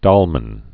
(dŏlmən)